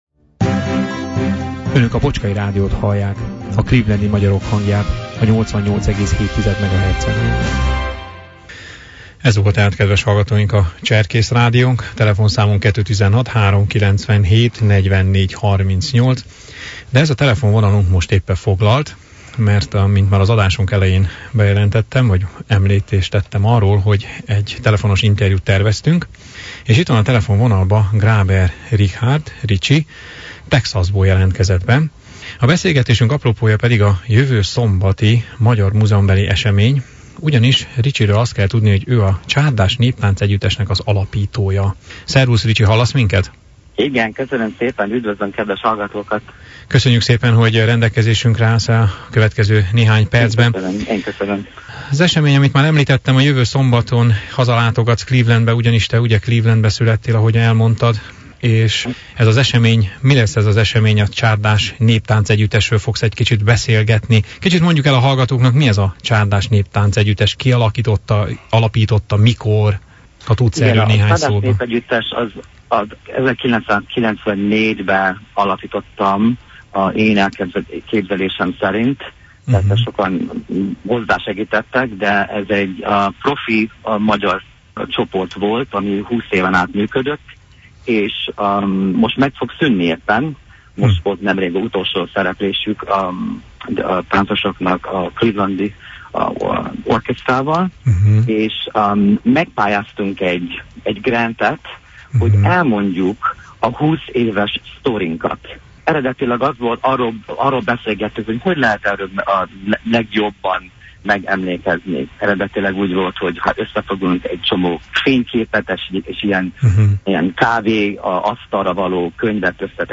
akik nem hallották a múlt heti interjút